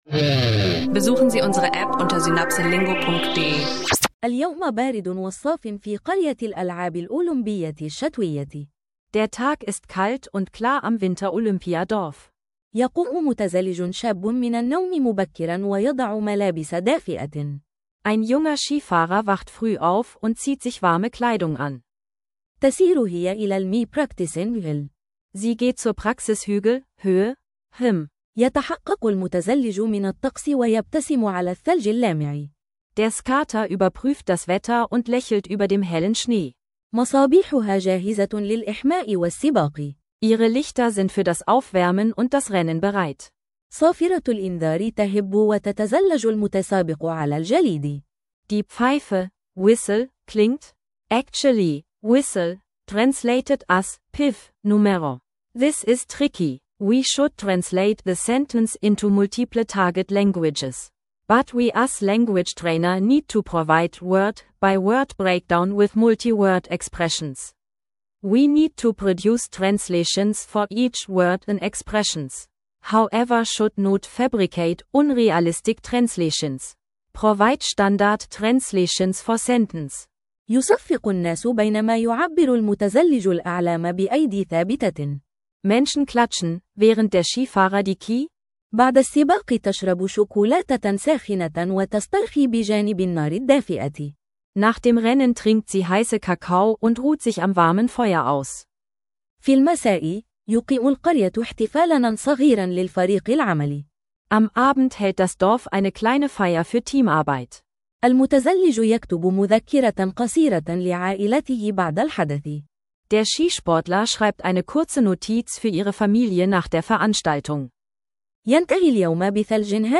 Zweisprachige Kurzgeschichten zu Sport, Olympische Spiele und Migration – praxisnahes Arabisch lernen im Alltag.